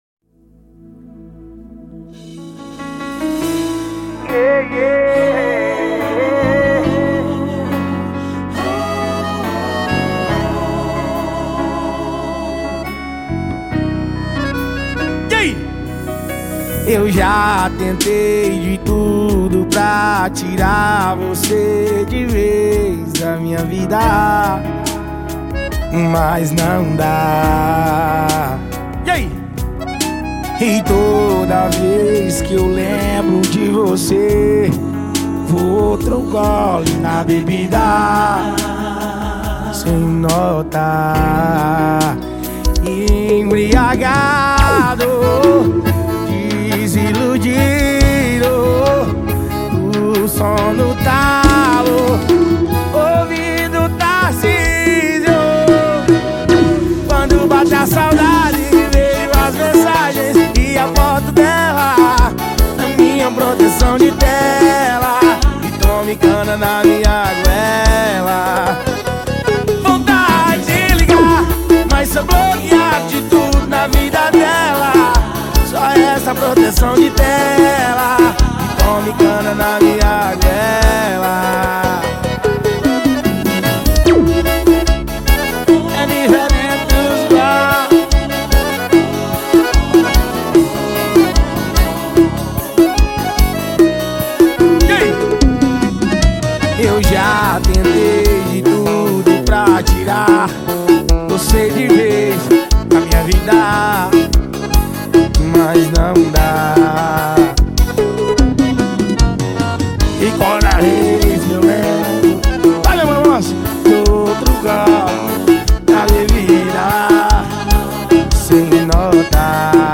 2024-07-14 00:32:19 Gênero: Sertanejo Views